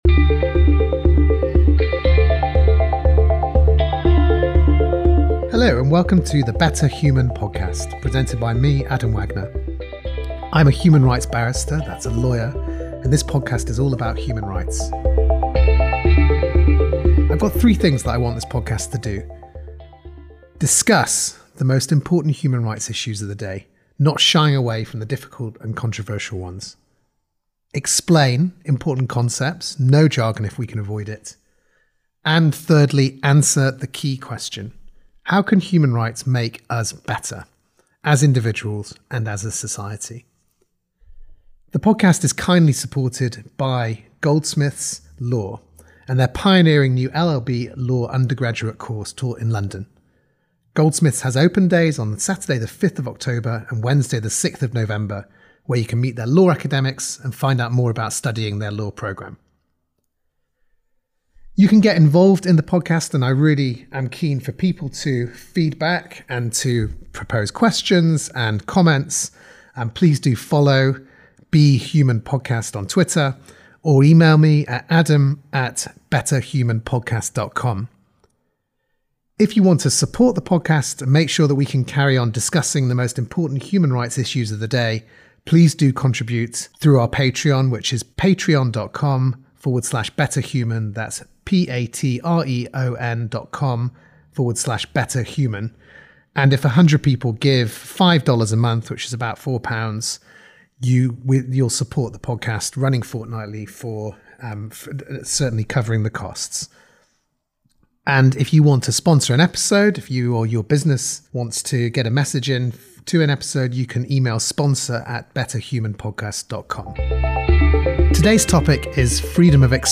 This podcast explores the most important human rights issues of the day through engaging and accessible interviews with high-profile guests and straightforward guides to key concepts and key events.